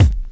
Kick 10.wav